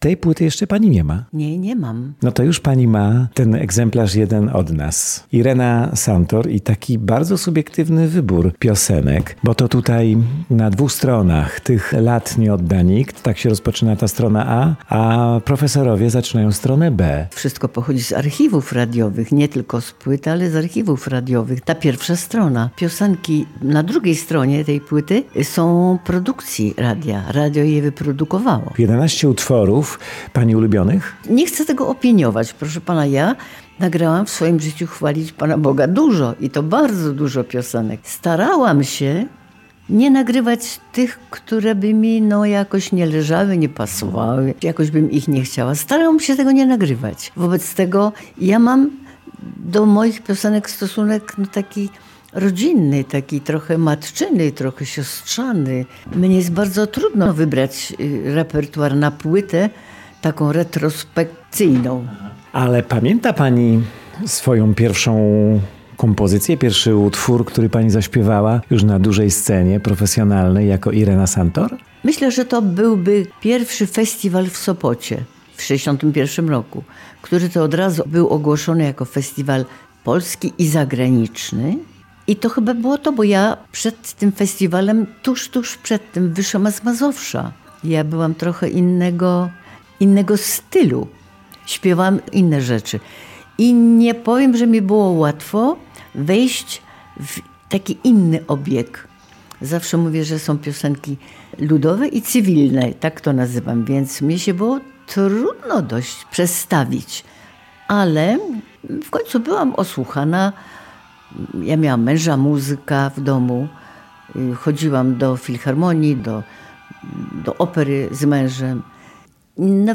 wywiad-irena-santor.mp3